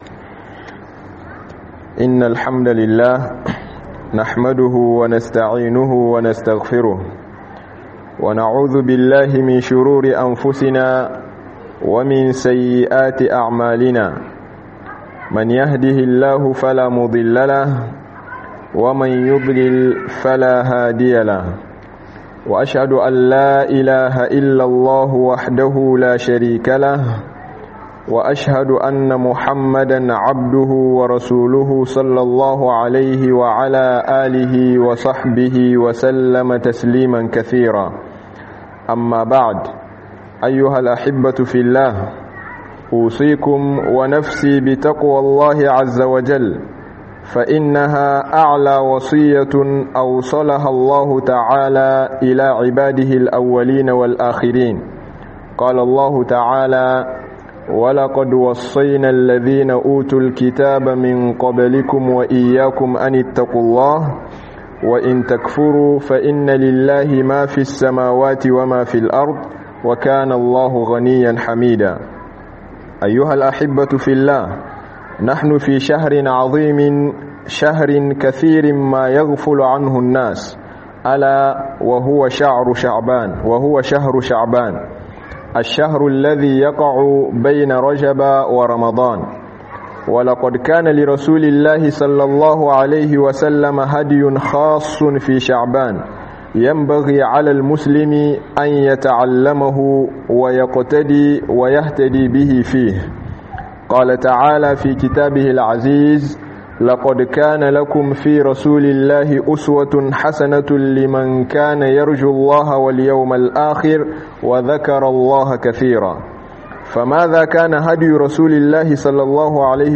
Watan sha'aban ba watan hutu bane - Hudubobi